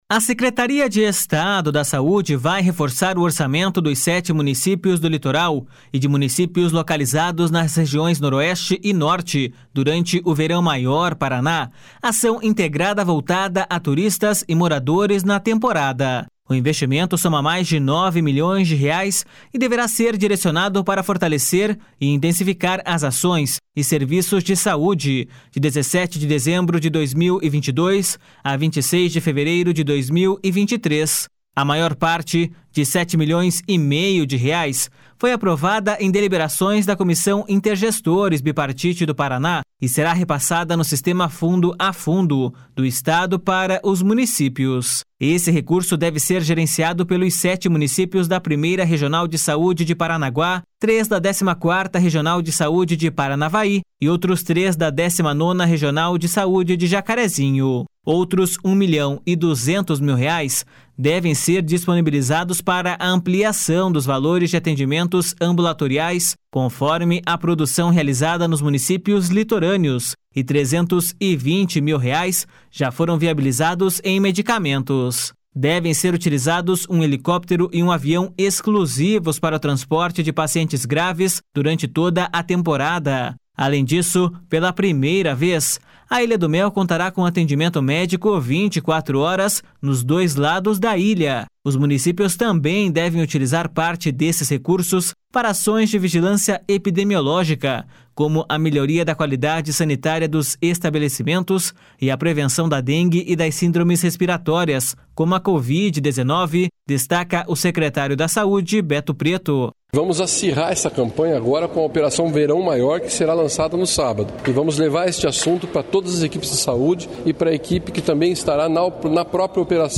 Além disso, pela primeira vez, a Ilha do Mel contará com atendimento médico 24 horas nos dois lados da ilha. Os municípios também devem utilizar parte desses recursos para ações de Vigilância Epidemiológica, como a melhoria da qualidade sanitária dos estabelecimentos e a prevenção da dengue e das síndromes respiratórias, como a Covid-19, destaca o secretário da Saúde, Beto Preto.// SONORA BETO PRETO.//
Repórter